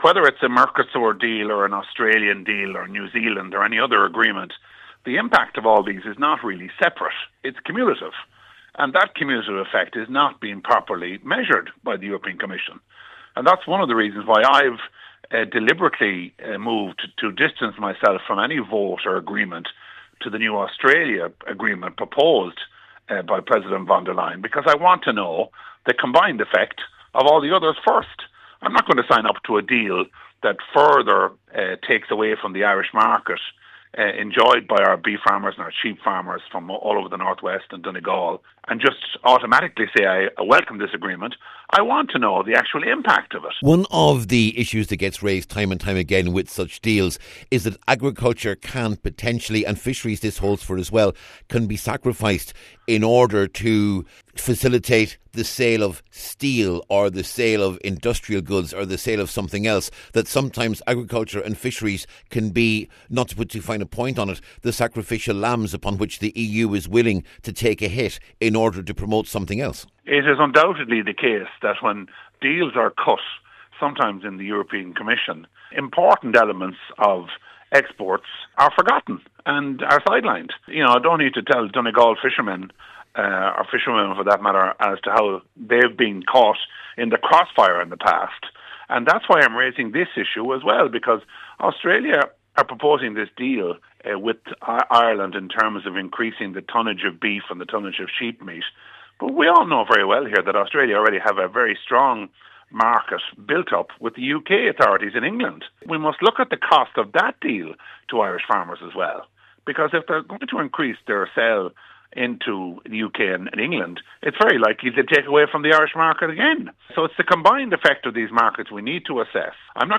Ciaran Mullooly was speaking after a new trade deal was signed with Australia this week by EU Commission President Ursula Van Der Leyen.